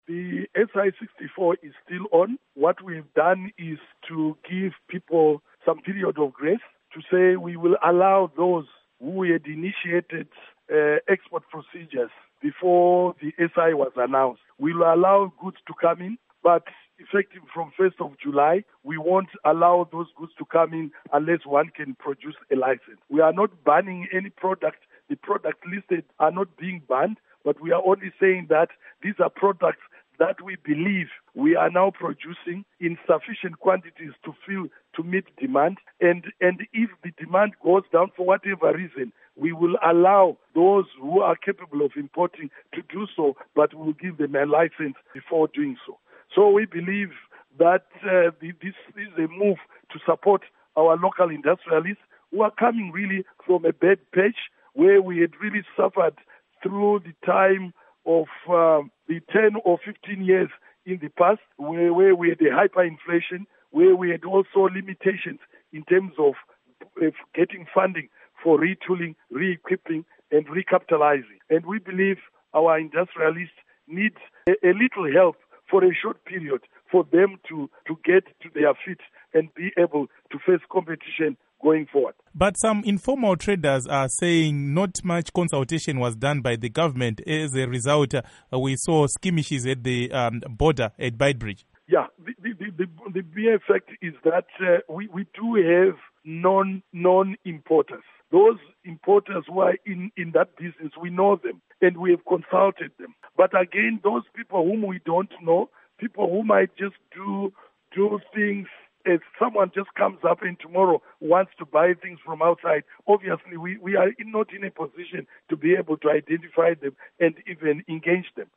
Interview With Minister Mike Bimha on Import Ban on Basic Commodities